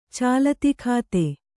♪ cālati khāte